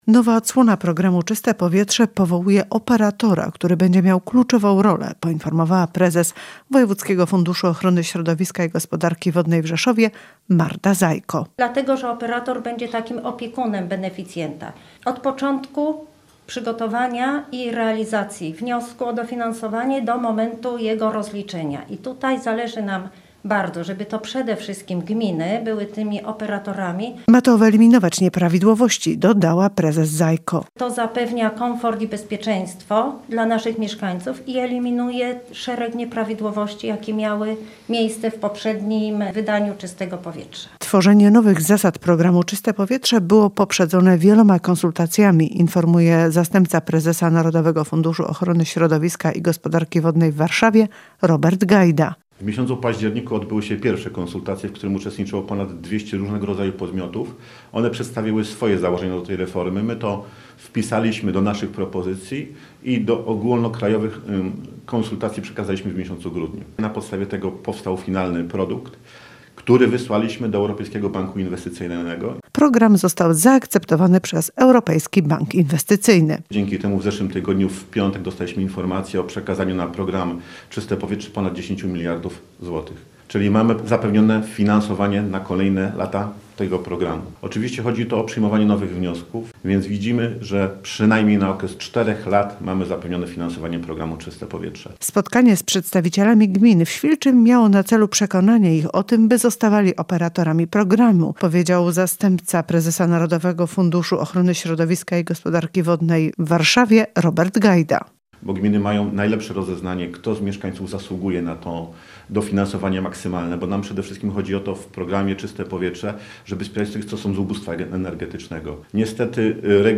– mówi Marta Zajko, prezes Wojewódzkiego Funduszu Ochrony Środowiska i Gospodarki Wodnej w Rzeszowie.